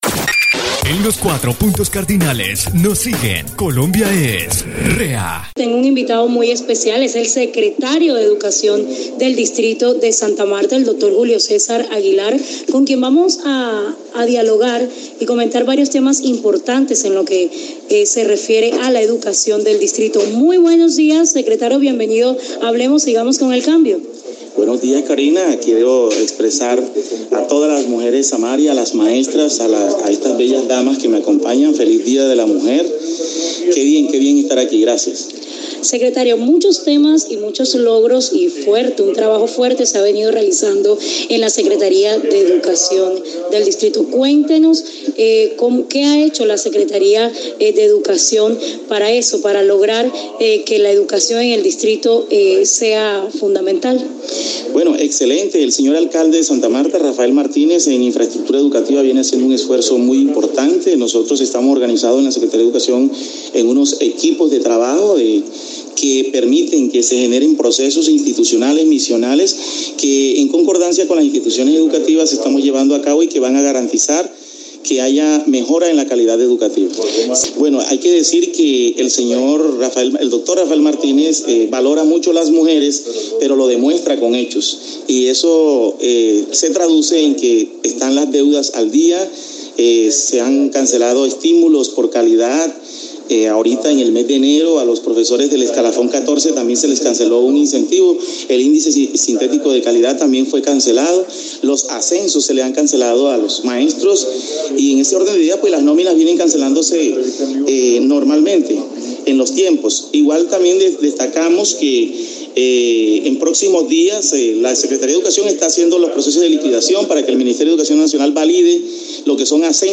Habla Julio Cesar Aguilar Carreño, actual Secretario de Educación Distrital, realiza importantes anuncios para el sector educativo entre ellos: